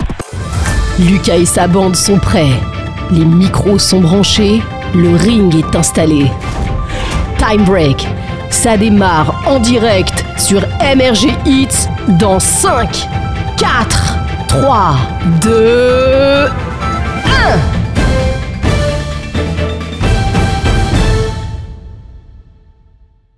Ils sont réalisés et produits par nos équipes en interne.
• Voix féminine / urbaine
GENERIQUE_TIME_BREAK.wav